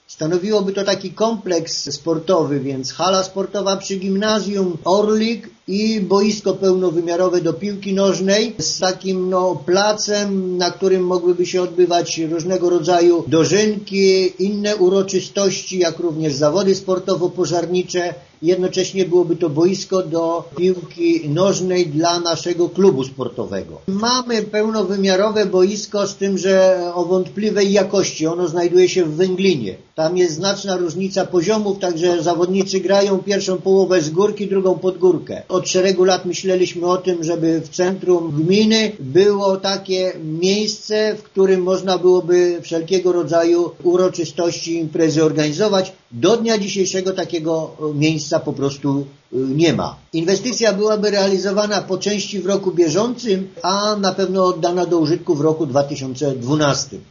„Stadion ma powstać przy „Orliku”wybudowanym w rejonie gimnazjum” – mówi wójt Franciszek Kwiecień: